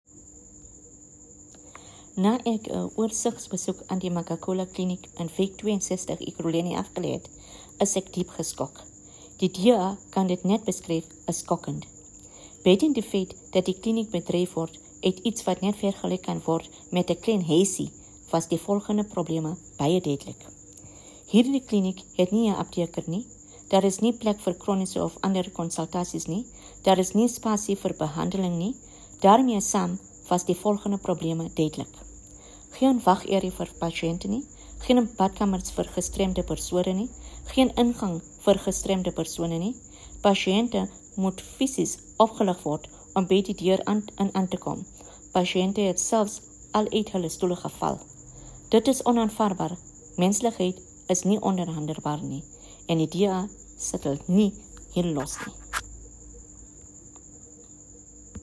Note to Editors: Please find English and Afrikaans soundbites by Haseena Ismail MP